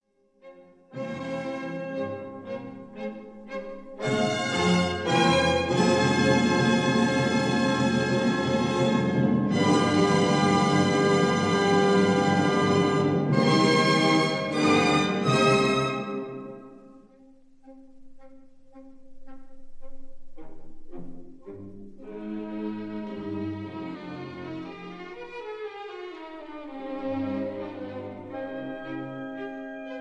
soprano
contralto
tenor
bass